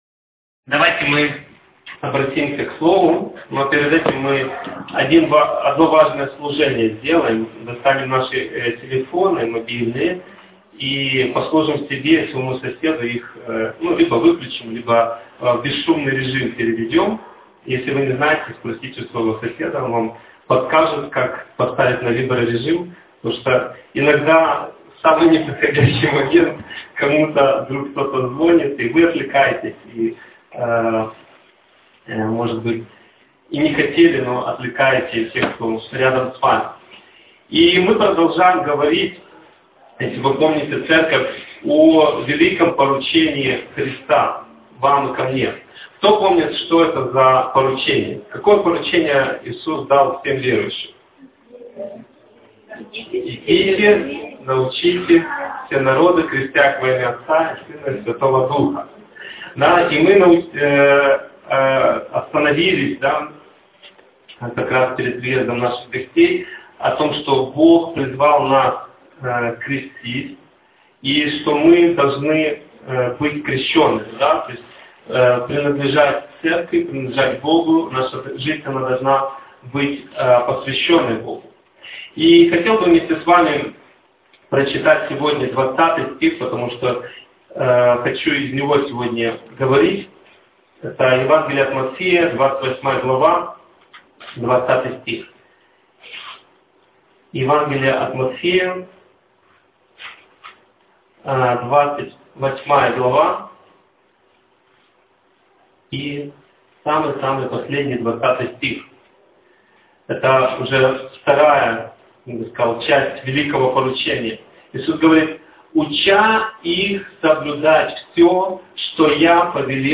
Актуальна проповідь